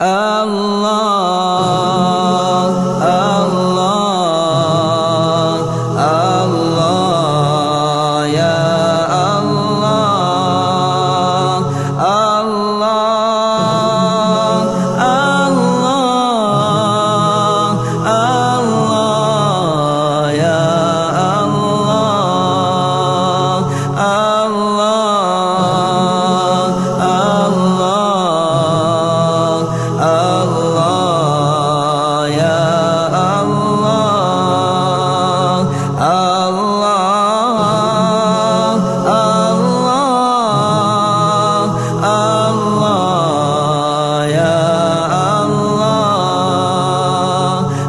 allah_dhikr.mp3